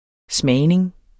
Udtale [ ˈsmæːjneŋ ]